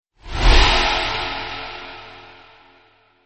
Slowdowning-Whoosh.mp3